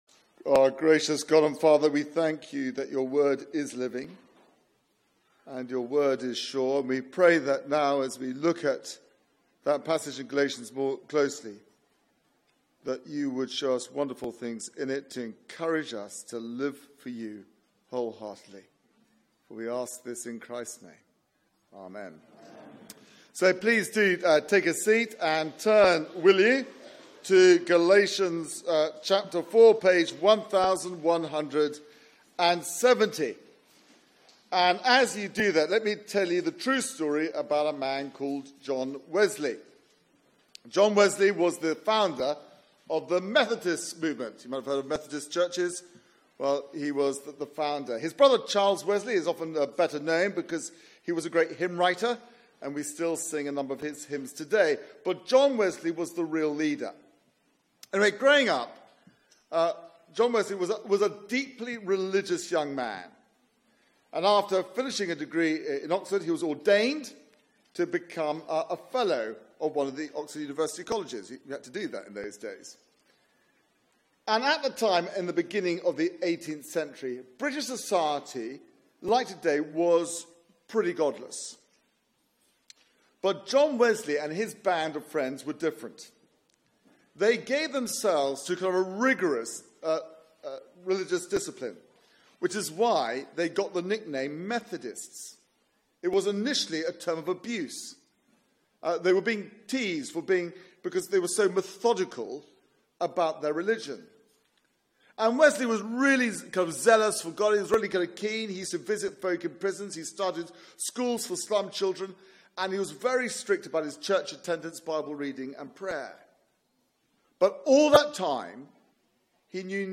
Media for 6:30pm Service on Sun 15th Oct 2017 18:30 Speaker
Sermon Search the media library There are recordings here going back several years.